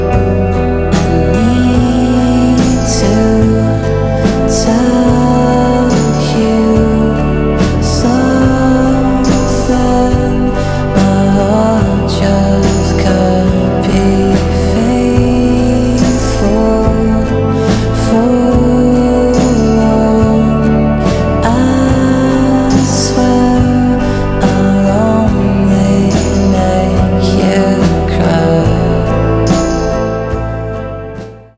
Красивый клубняк
Electronic
EDM
Progressive house Женский голос Транс Мощные басы